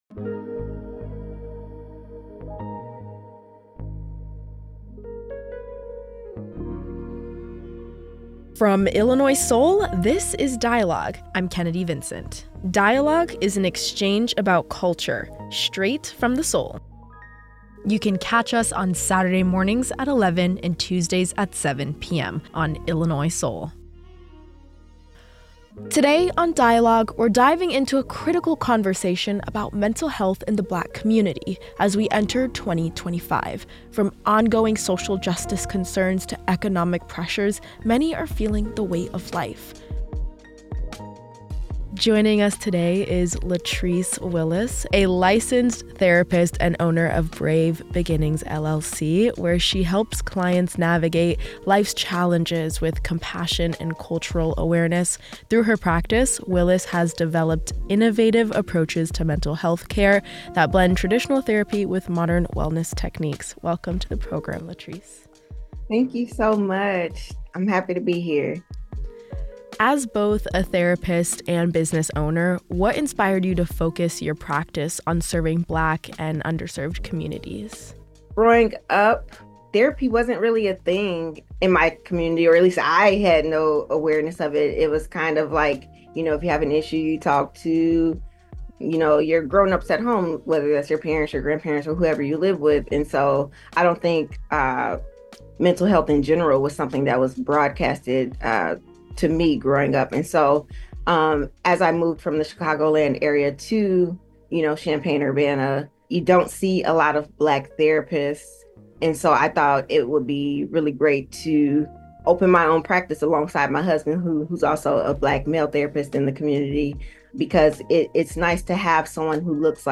Hear from two therapists on staying resilient and a Grammy award-winning artist shares their journey and newest project.